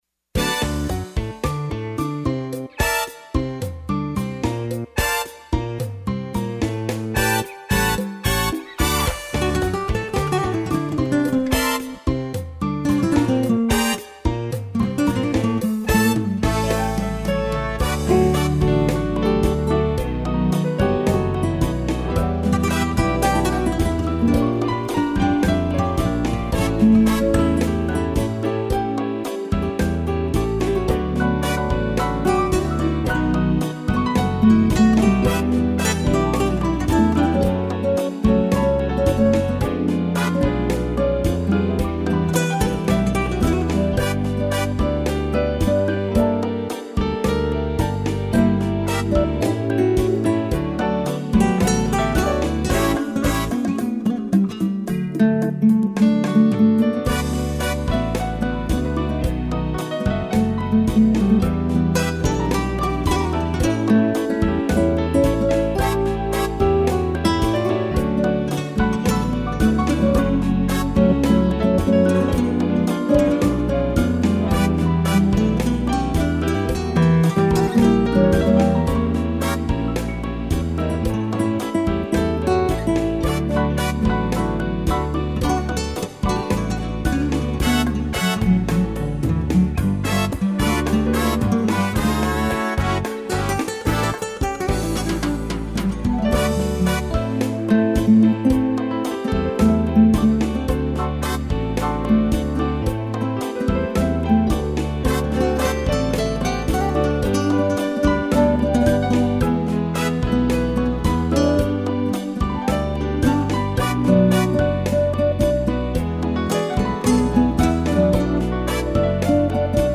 violão
piano